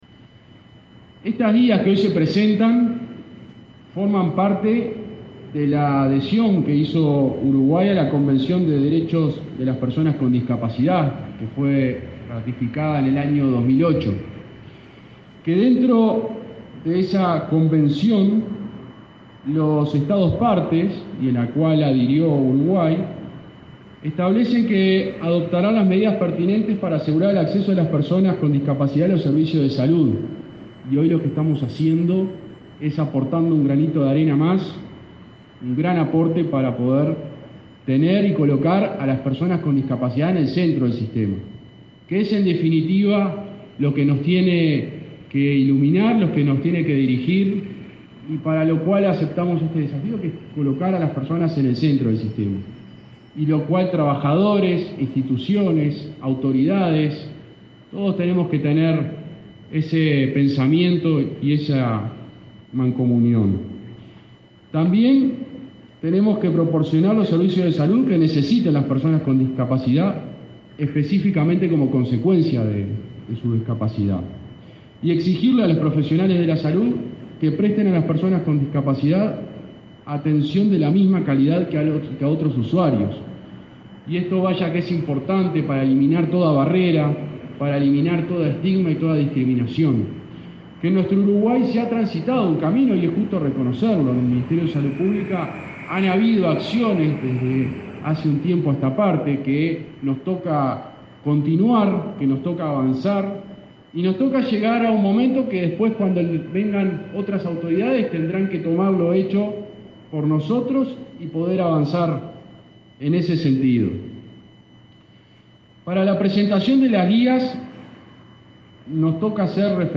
Palabras del subsecretario de Salud Pública y del ministro de Desarrollo Social